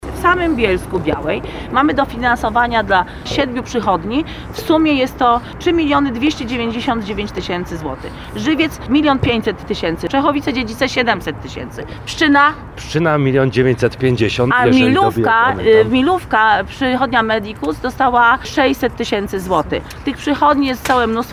Informację o pieniądzach dla POZ-ów przekazała podczas konferencji prasowej zorganizowanej przed przychodnią Troclik na os. Karpackim w Bielsku-Białej.